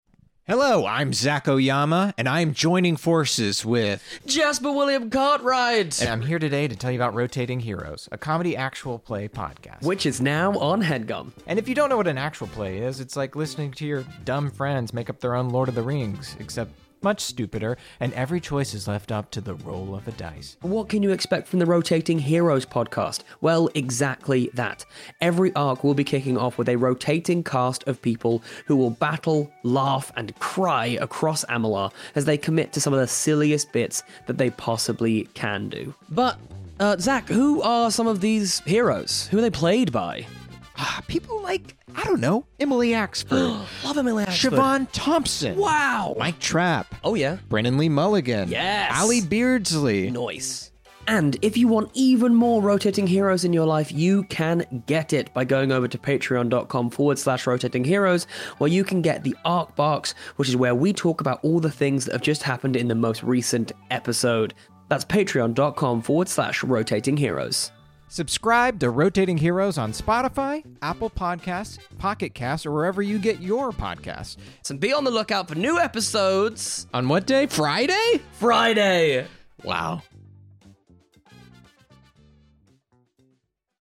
Follow the adventures of a rotating cast featuring the funniest comedians, actors and improvisors around.